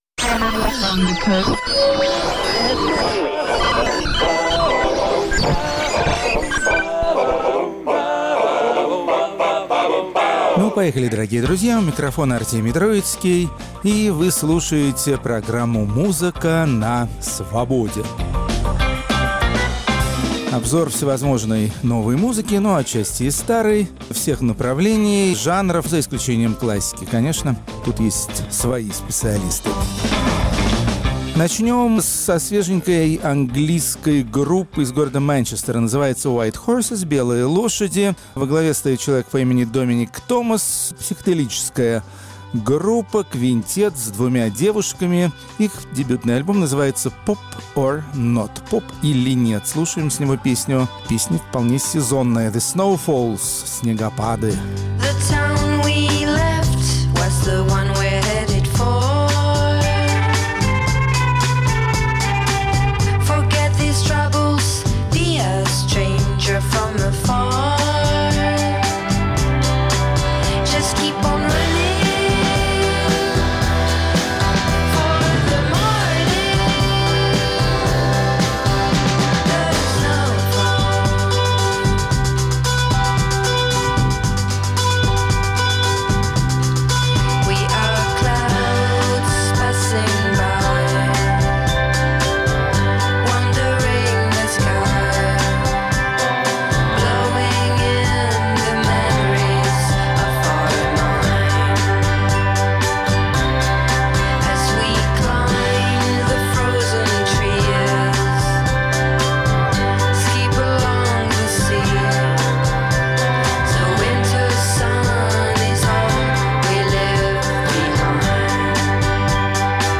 Рок-критик Артемий Троицкий встречает рождественские праздники.